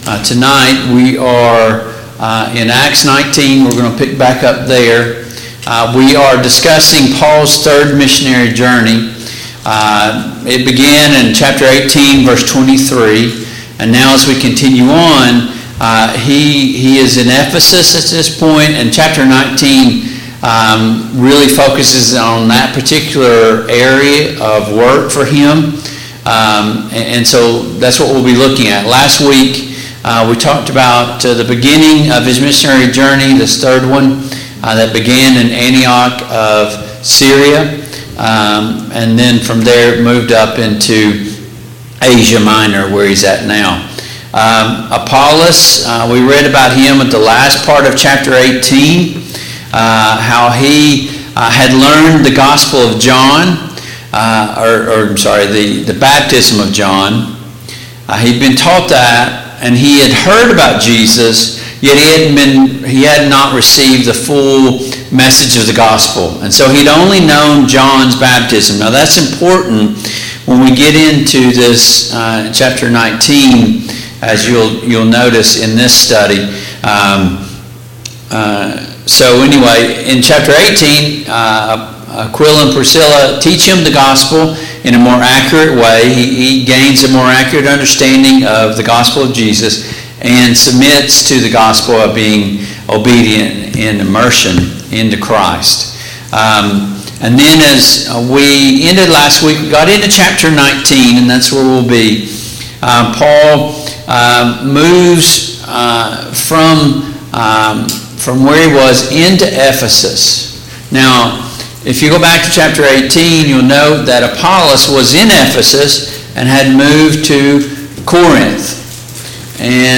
Acts 19:1-20 Service Type: Mid-Week Bible Study Download Files Notes « Living on Empty 3.